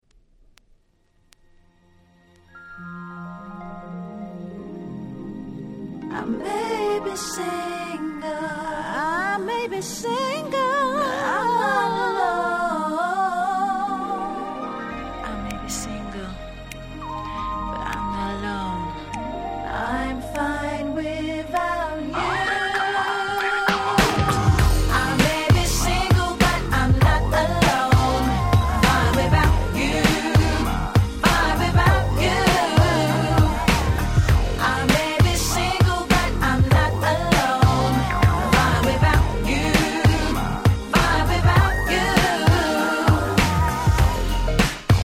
UK R&Bや90's R&Bが好きならど真ん中なはず！！